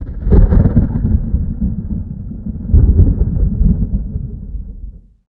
thunder7.ogg